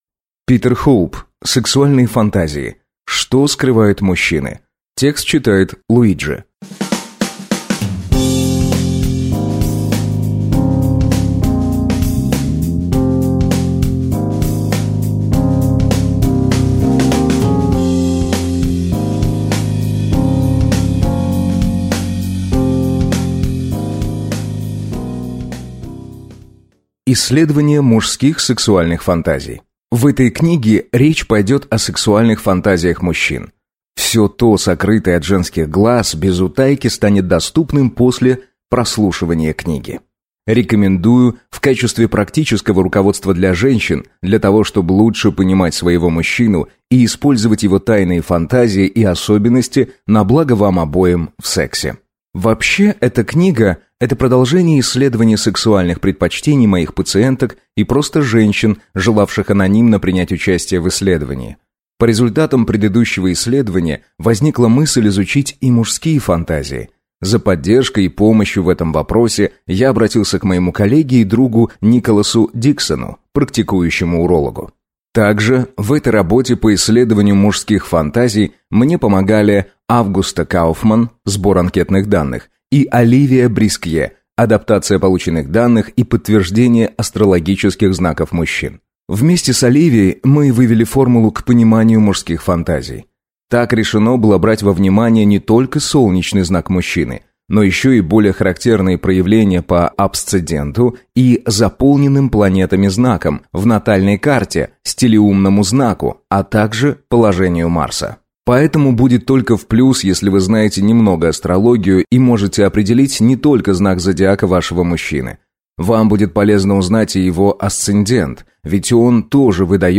Aудиокнига Сексуальные фантазии. Что скрывают мужчины?